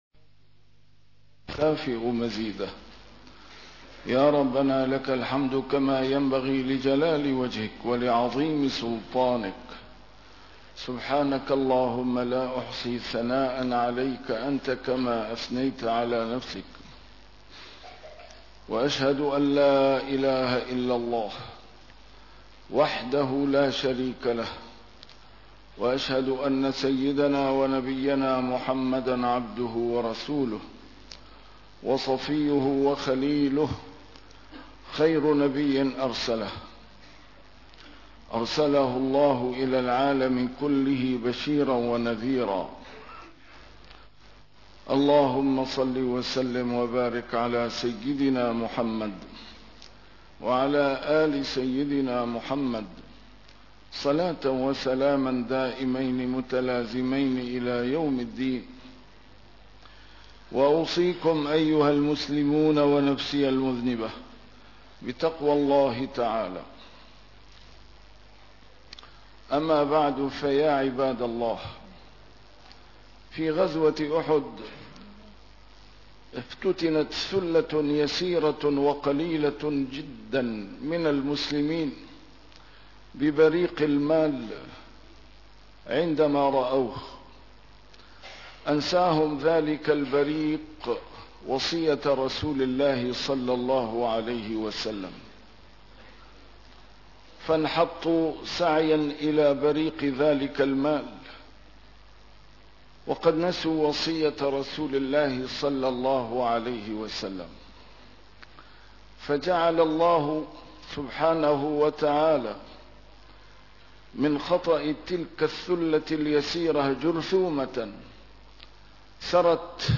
نسيم الشام › A MARTYR SCHOLAR: IMAM MUHAMMAD SAEED RAMADAN AL-BOUTI - الخطب - قَدْ أَصَبْتُمْ مِثْلَيْها قُلْتُمْ أَنَّى هَذا